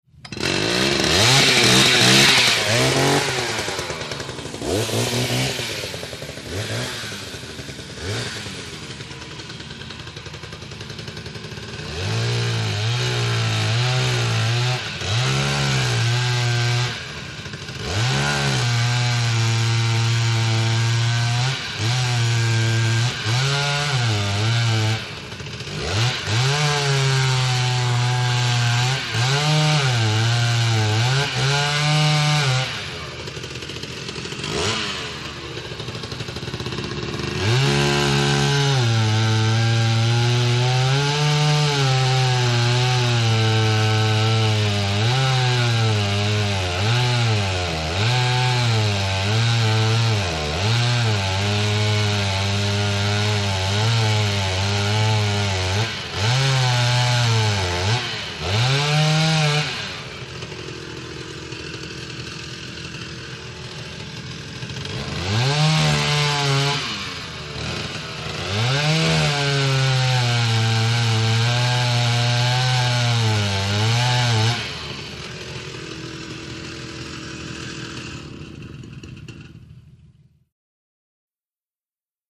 ChainSawCuts PE699301
MACHINES - CONSTRUCTION & FACTORY CHAINSAW: EXT: Large chain saw, start, multiple cutting takes, idle, switch off.